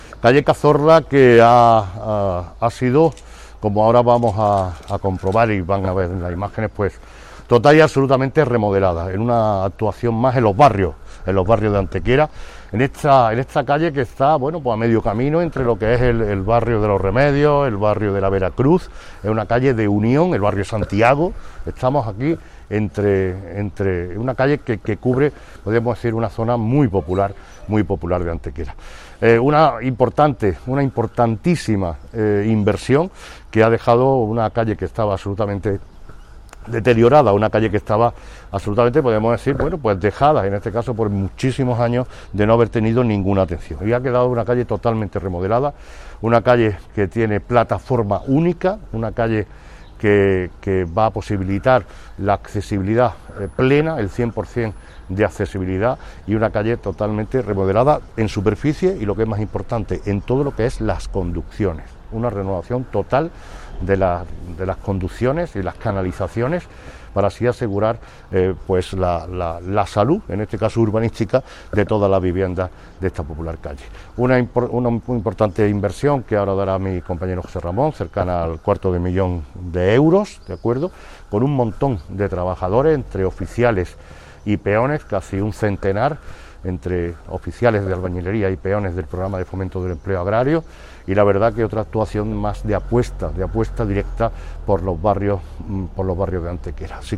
El alcalde de Antequera, Manolo Barón, y el concejal delegado de Obras, José Ramón Carmona, han informado hoy en rueda de prensa de la conclusión de las obras de mejora y remodelación integral de la calle Cazorla en el barrio Santiago de nuestra ciudad, en lo que respecta al tramo comprendido entre las intersecciones con la cuesta Merino y la puerta de Granada.
Cortes de voz